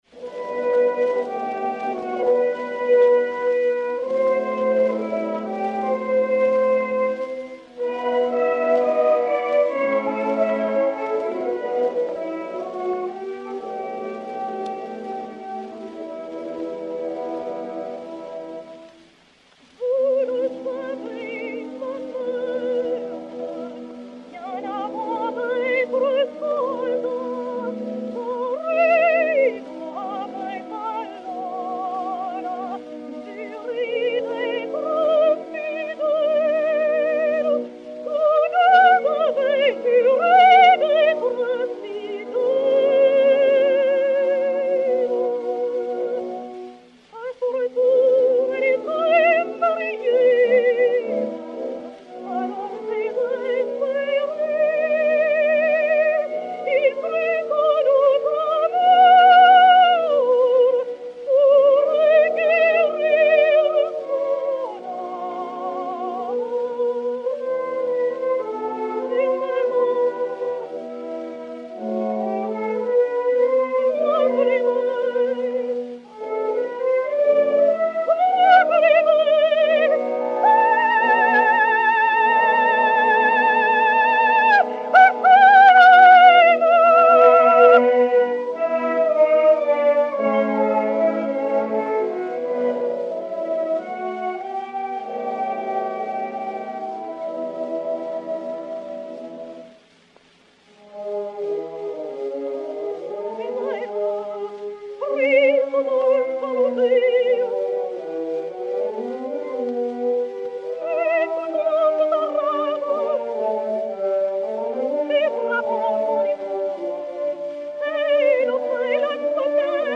Grand air "Vous le savez, ma mère"
Rose Heilbronner (Santuzza) et Orchestre
XP 4983, enr. à Paris vers 1912